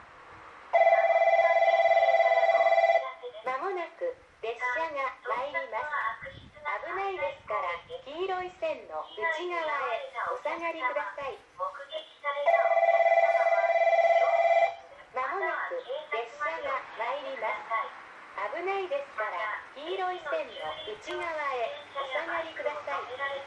この駅では接近放送が設置されています。
接近放送普通　鹿児島中央行き接近放送です。